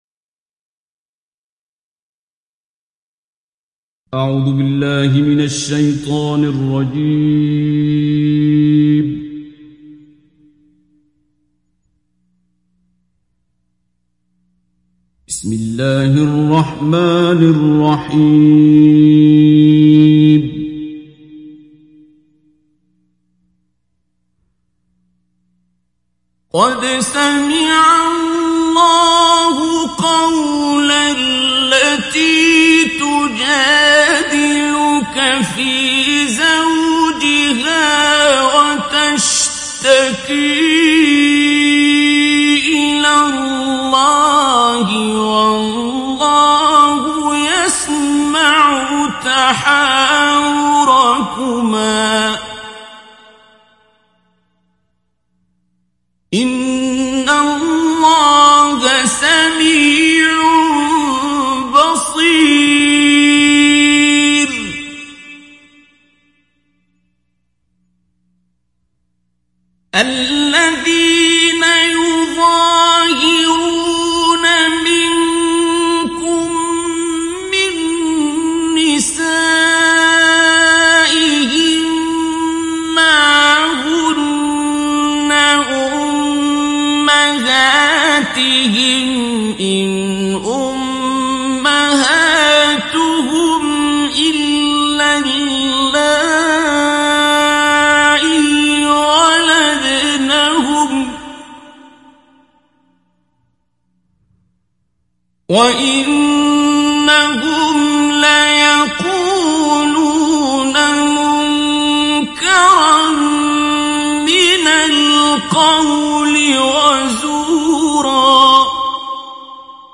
دانلود سوره المجادله mp3 عبد الباسط عبد الصمد مجود روایت حفص از عاصم, قرآن را دانلود کنید و گوش کن mp3 ، لینک مستقیم کامل
دانلود سوره المجادله عبد الباسط عبد الصمد مجود